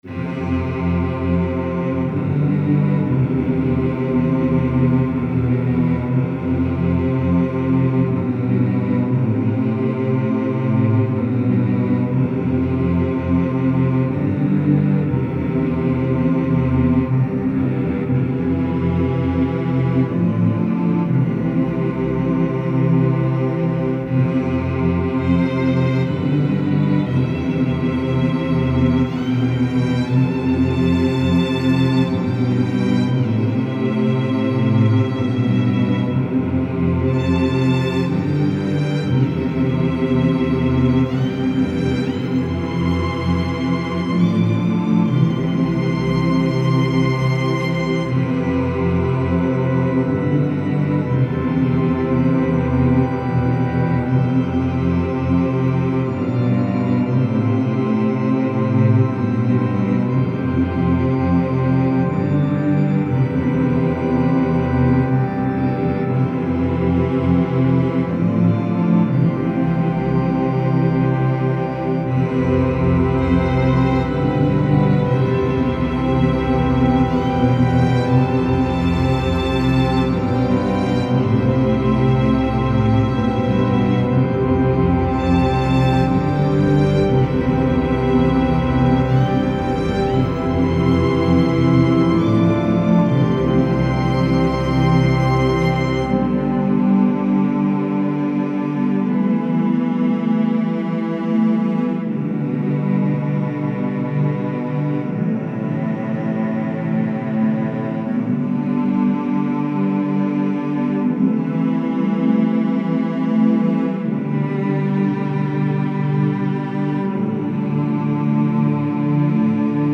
Style Style Soundtrack
Mood Mood Intense
Featured Featured Cello, Choir, Organ +1 more
BPM BPM 120